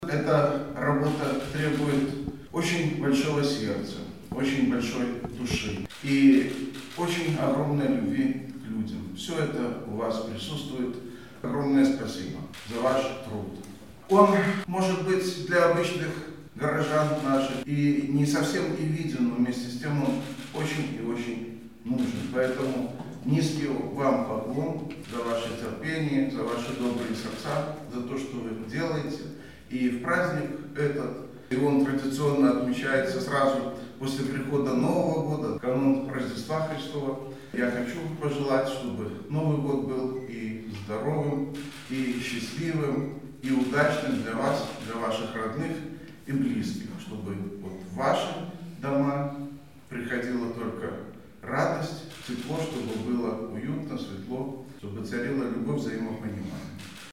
Председатель Барановичского горисполкома Юрий Громаковский поздравил работников соцзащиты с профессиональным праздником (+AUDIO)
Представителей благородной профессии поздравил председатель городского исполнительного комитета. Юрий Громаковский адресовал виновникам торжества слова благодарности за труд.